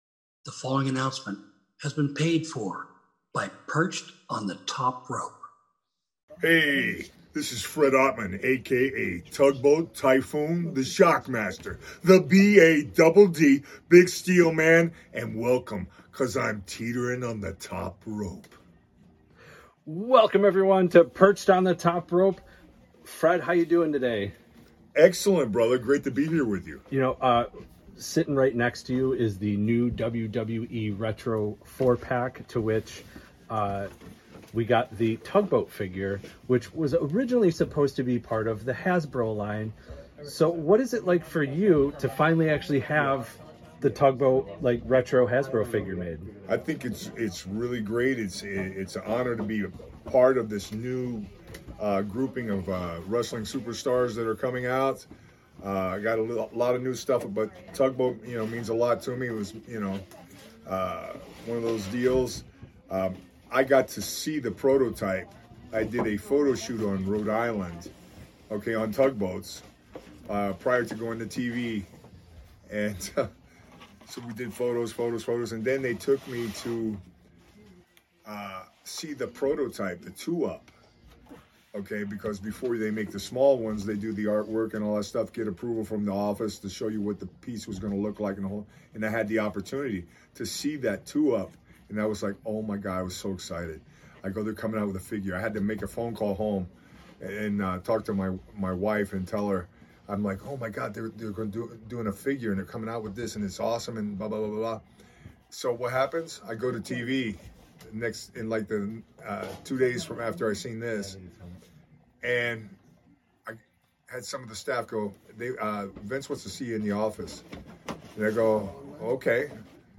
E230: Interview With Fred Ottman, Talks WWE Figures, Vince McMahon Changing His Character, WWE Legends, Cody Rhodes from Perched On The Top Rope | Podcast Episode on Podbay